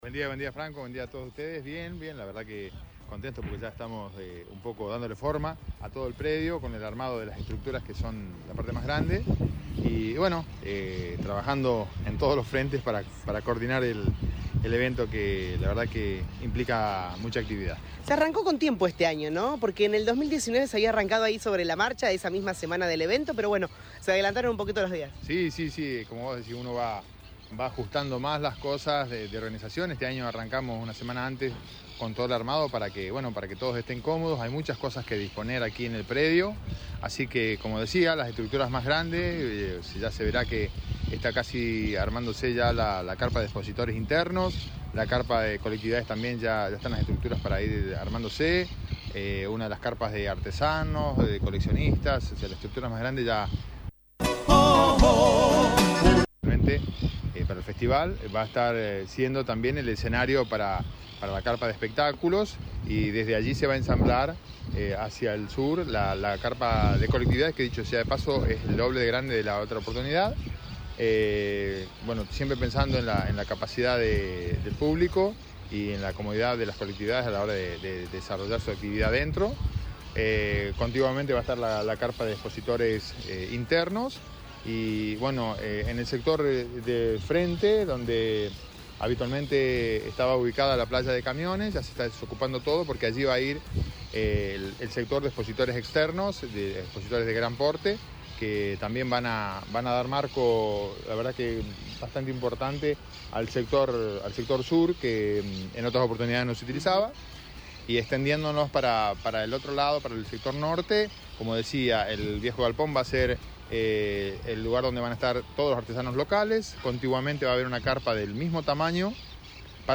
Germán Argañaraz dialogó con LA RADIO y adelantó detalles sobre la Fiesta de Colectividades, Expo Comercial y festejos 130 años.